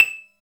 39 MARIMBA-L.wav